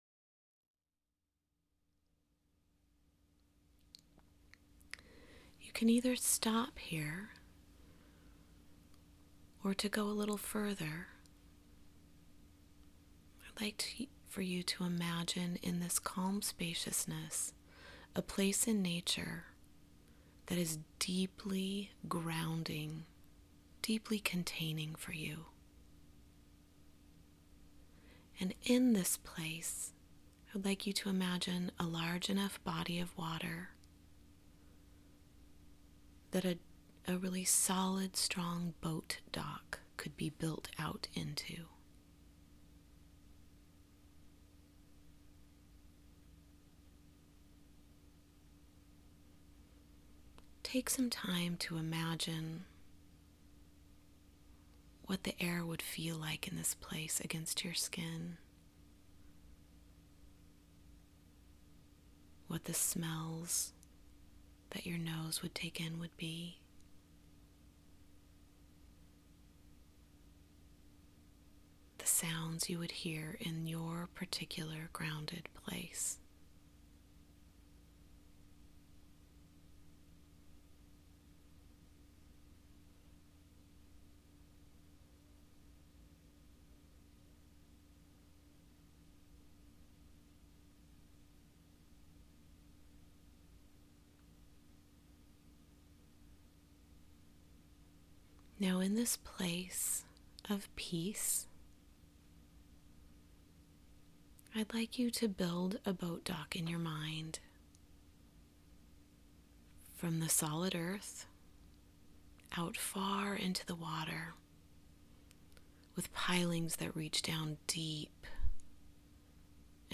part 2 is a breath meditation.